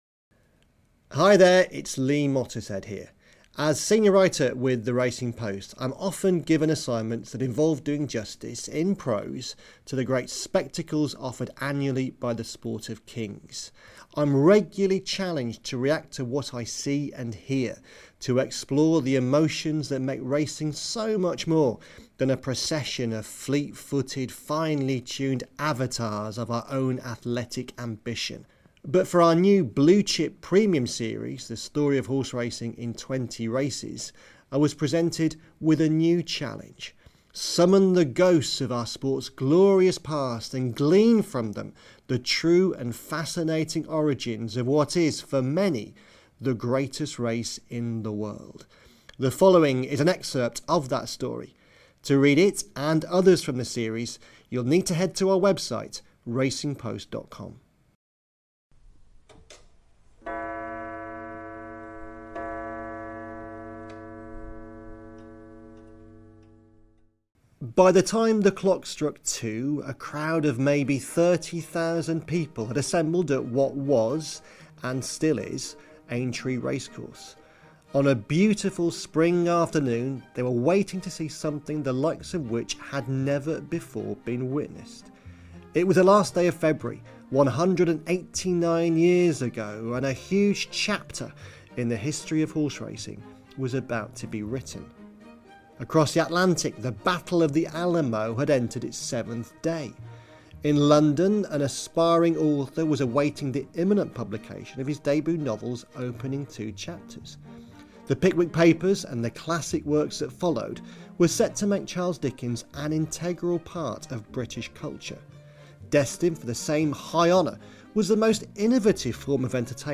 reads an extract from the second entry of our weekly series 'The Story Of Racing in 20 Races' - taking us back in time to Aintree for the true birth of the Grand National.